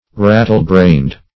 Rattle-brained \Rat"tle-brained`\ (r[a^]t"t'l*br[=a]nd`), a.